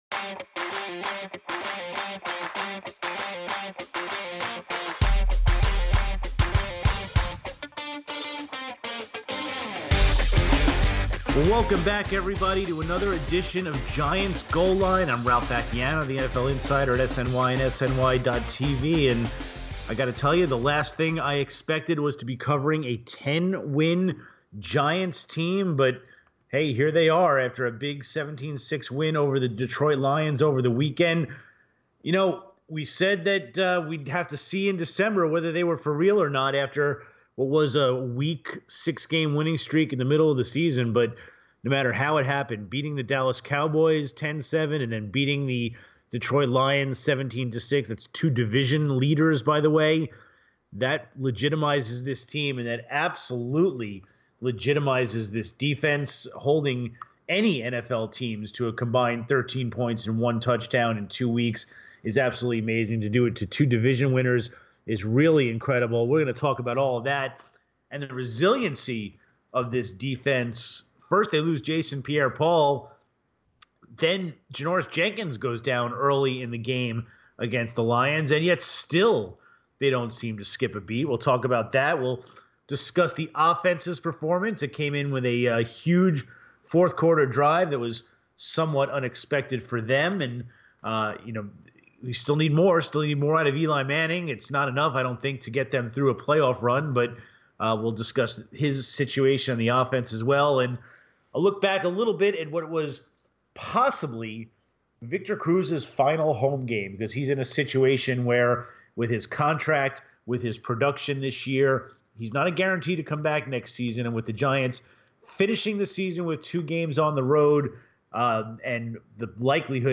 Later, former Giants punter Jeff Feagles calls in to talk about the team’s offensive struggles and the comparisons between this team and the 2007-08 Super Bowl winning squad.